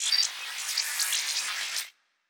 pgs/Assets/Audio/Sci-Fi Sounds/Electric/Data Calculating 4_5.wav at master
Data Calculating 4_5.wav